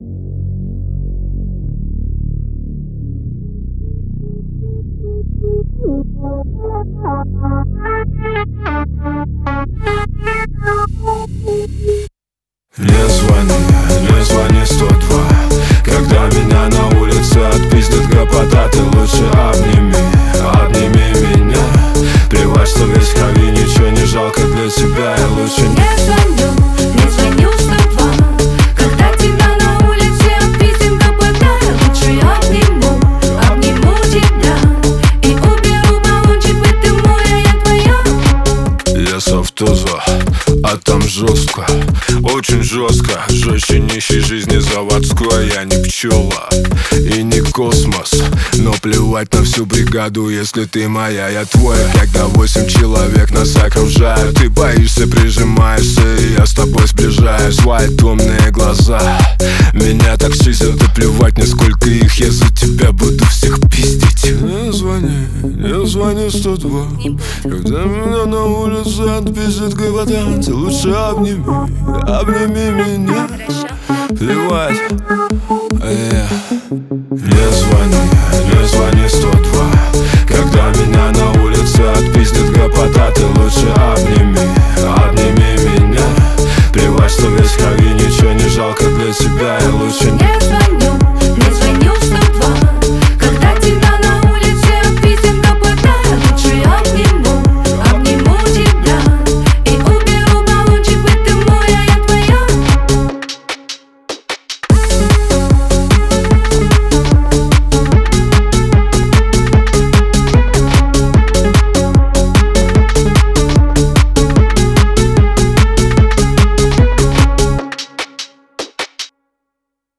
Поп, Русская музыка, Русский поп